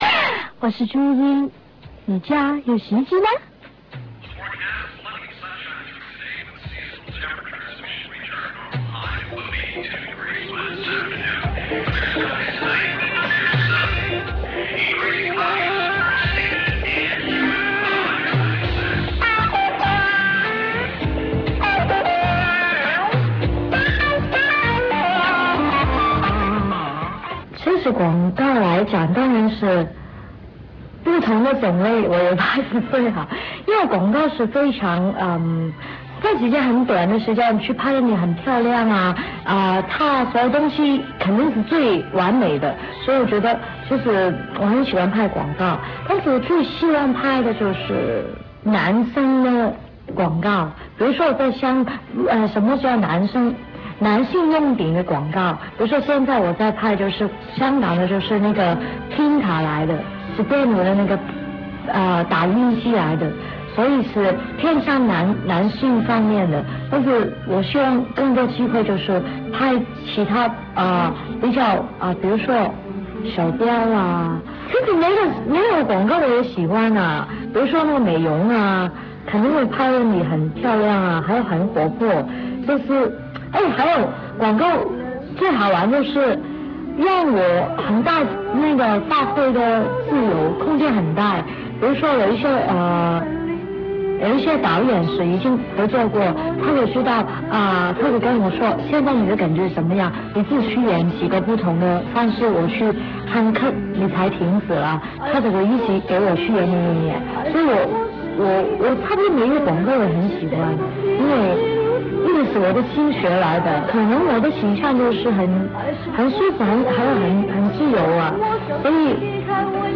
Article: Audio clip of interview with Athena on M-Discovery.